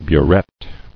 [bu·rette]